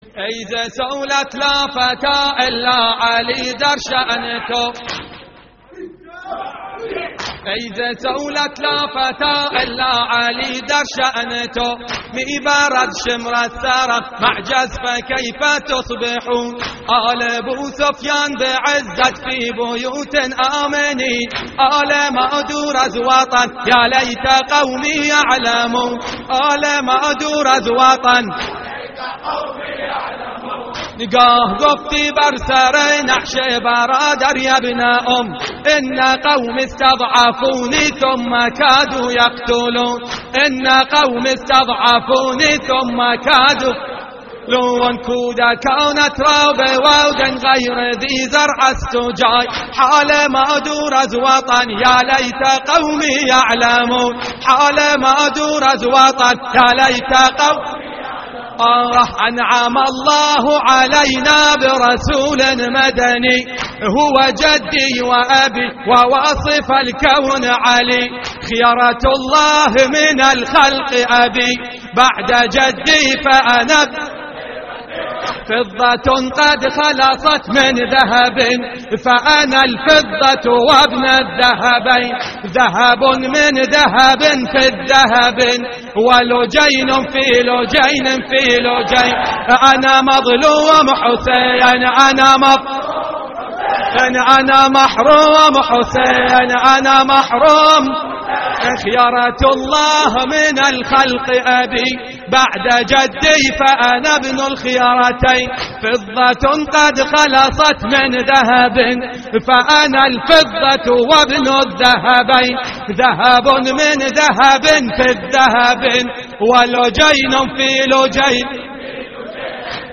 اللطميات الحسينية
عربي وفارسي